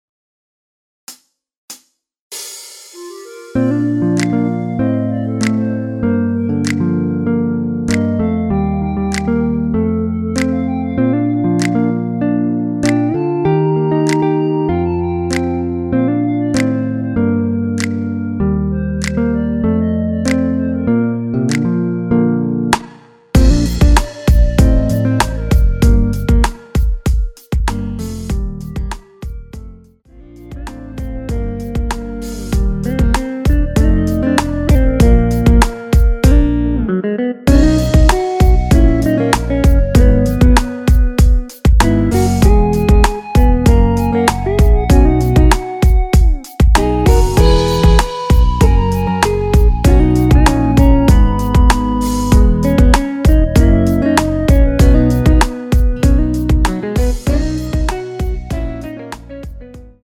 키 Bb 가수